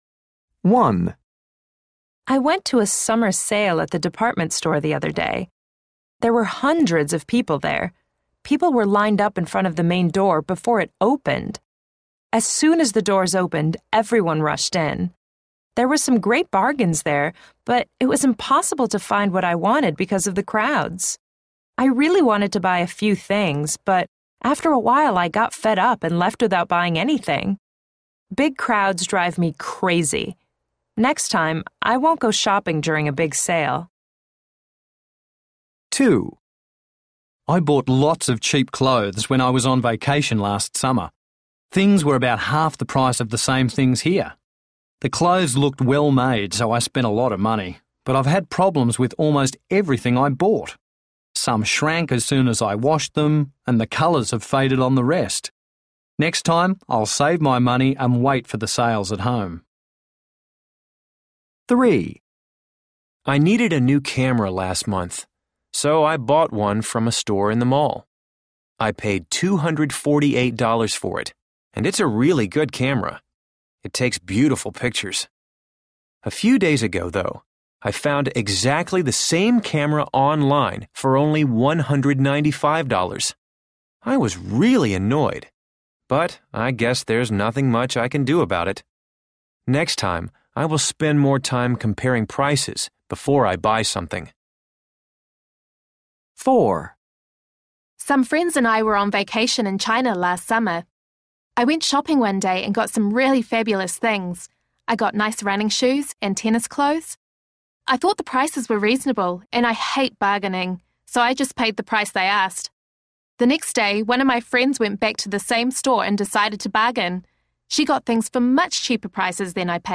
A. People are talking about shopping.